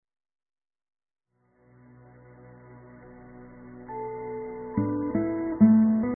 诵经
佛音 诵经 佛教音乐 返回列表 上一篇： 心经-现场版 下一篇： 大悲咒-心灵港湾 相关文章 貧僧有話14說：我的新佛教改革初步--释星云 貧僧有話14說：我的新佛教改革初步--释星云...